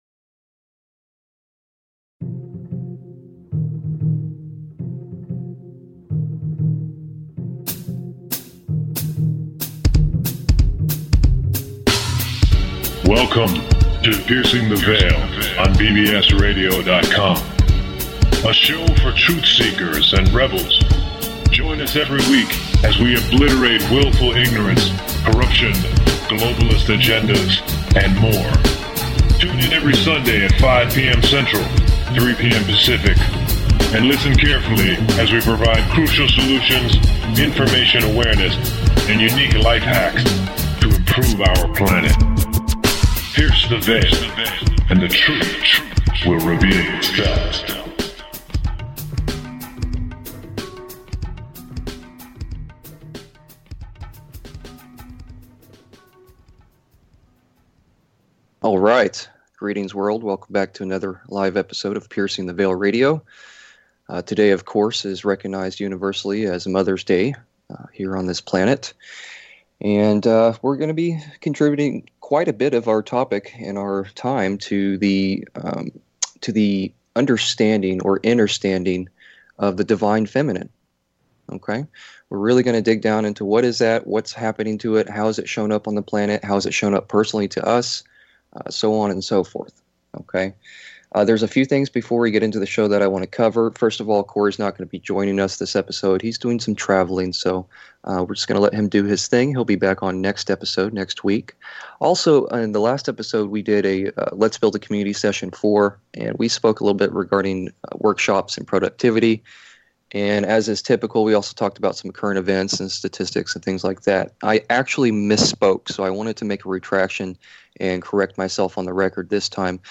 A lively conversation about the DIVINE FEMININE ensues.